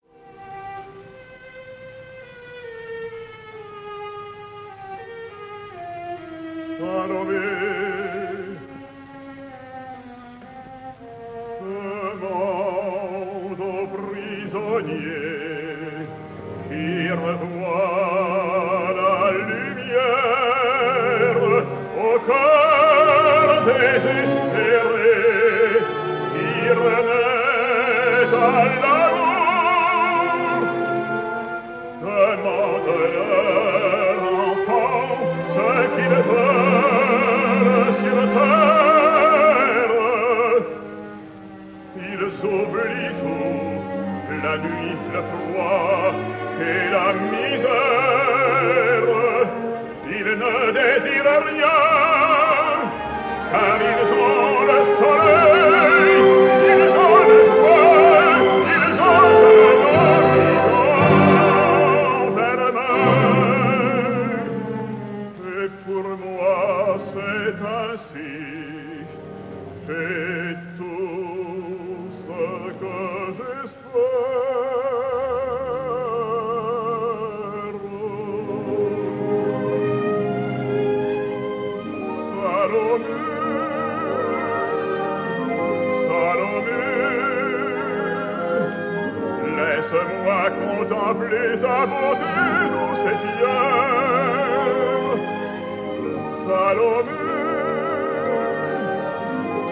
MALIBRAN的確是老錄音，而且大多接近百年前的錄音，
但大部分的音質都不差，在情感、音色表現上都沒有問題。
再把不同演唱者的版本放入，這些演唱者都是當時的一時之選。